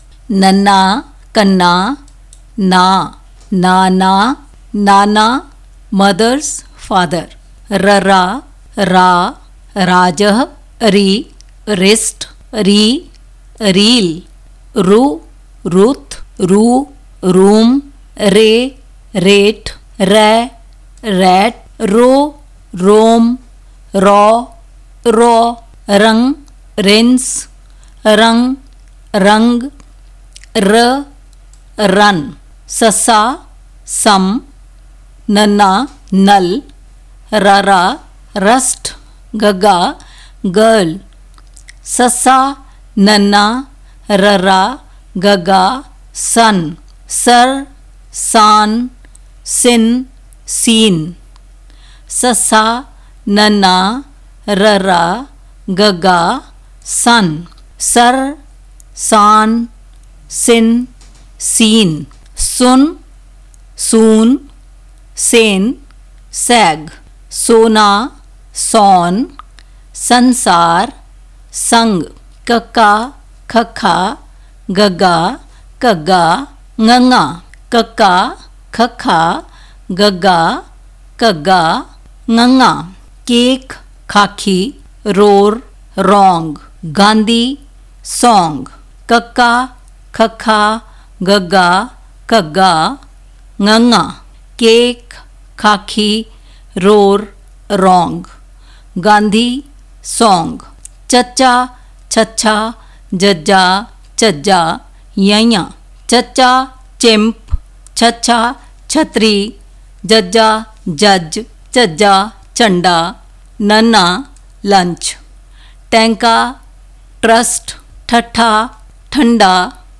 Lesson 1